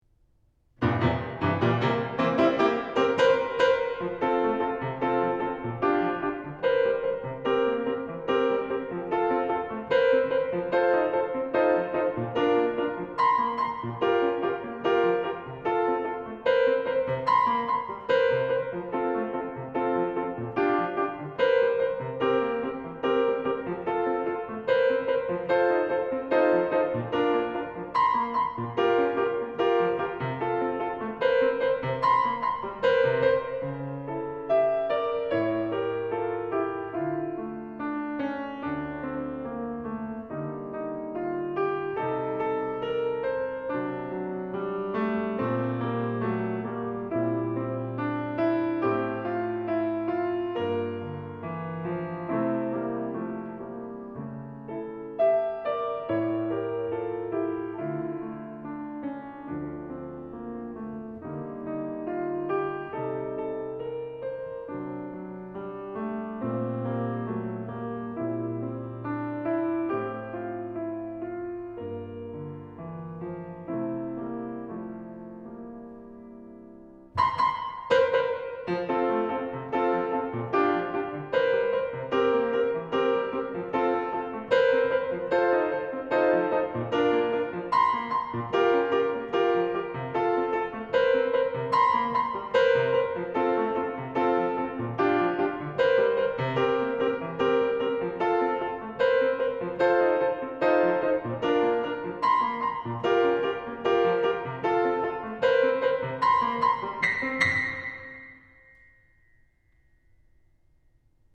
Genre :  ChansonComptine
Style :  Avec accompagnement
Enregistrement piano